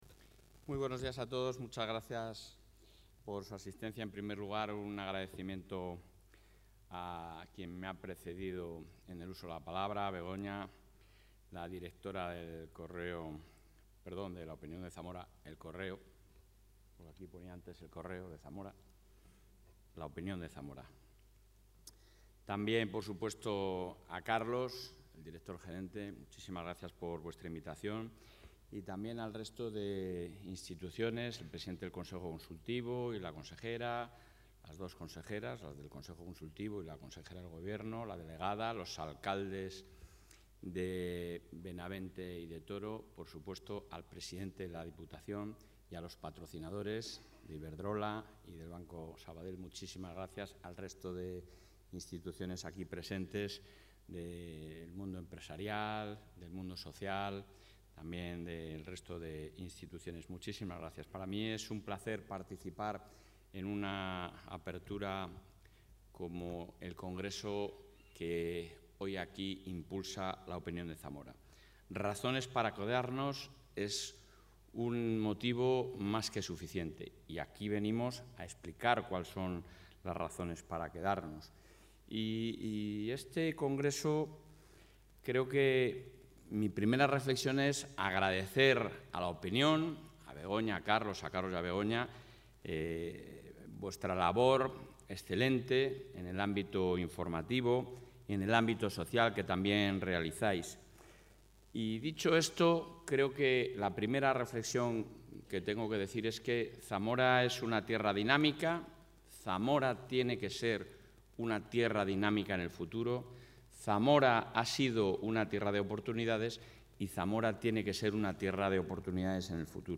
El presidente de la Junta de Castilla y León, Alfonso Fernández Mañueco, ha participado en el IV Congreso ´Razones para...